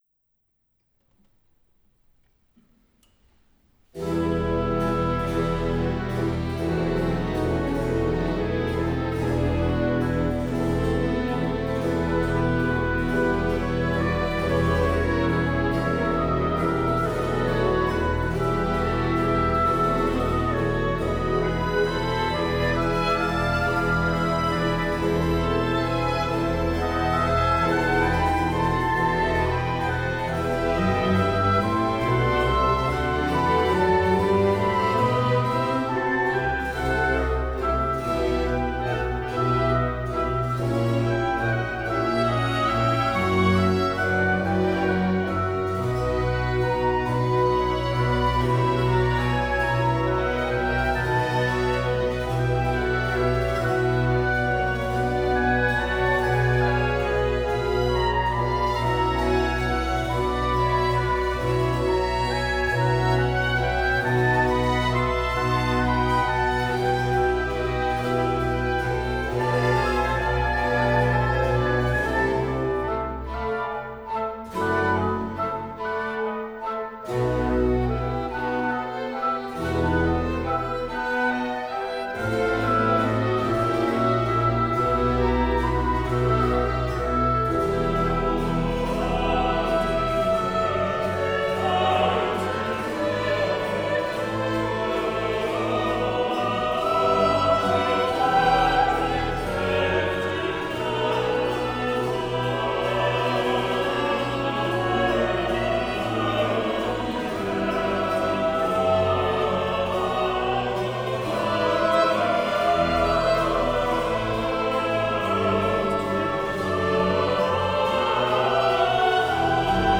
enregistrement haute définition en « live »